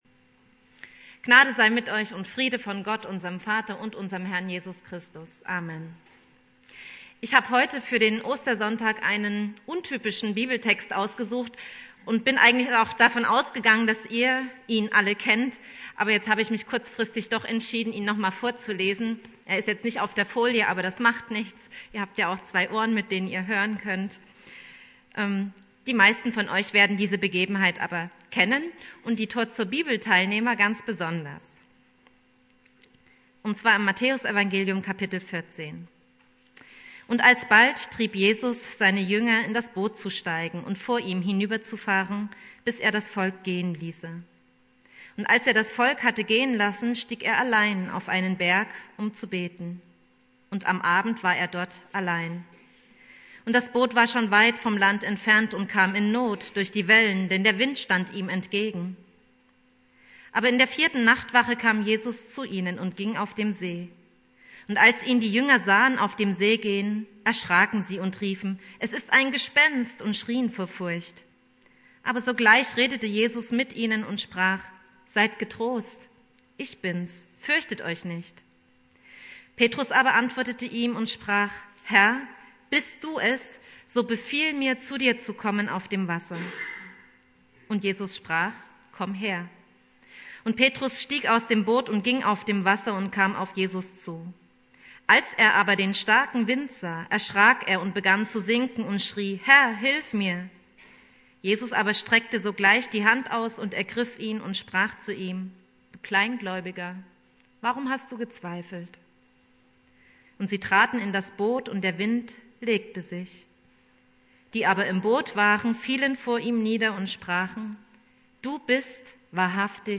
Predigt
Ostersonntag